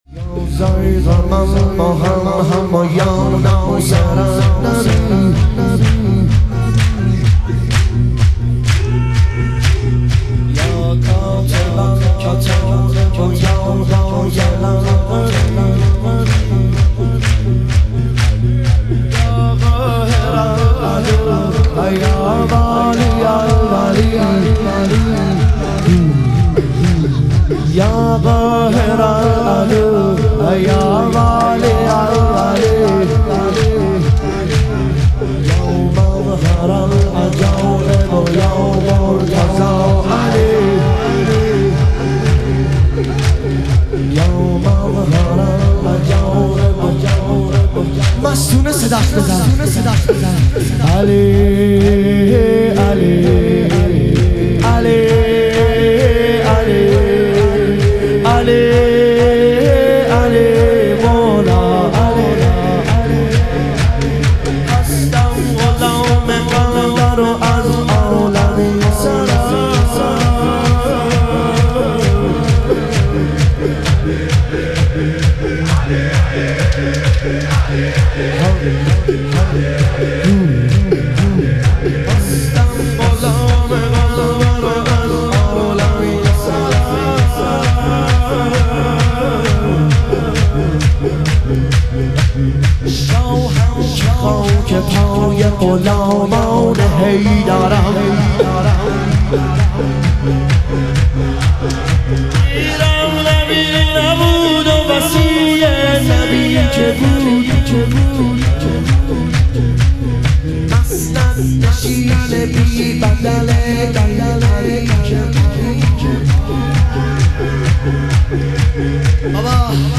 شهادت امام صادق علیه السلام - شور